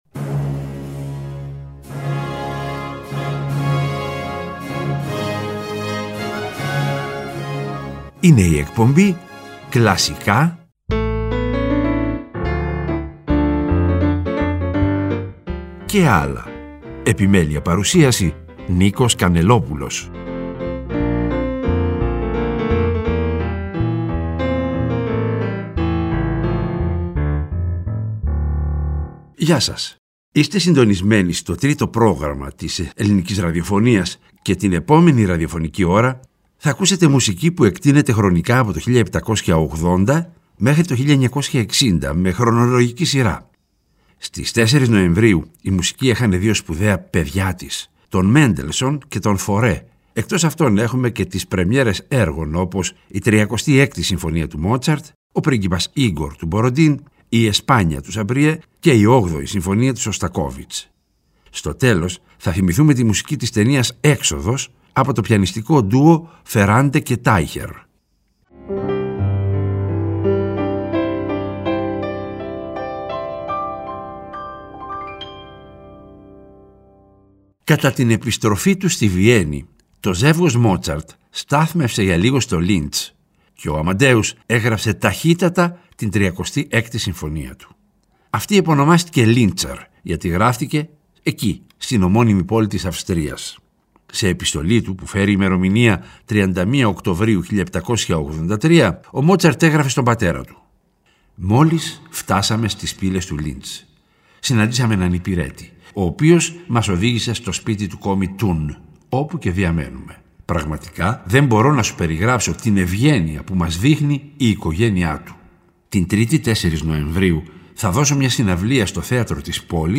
Μουσική που εκτείνεται χρονικά από το 1780 μέχρι το 1960, με χρονολογική σειρά.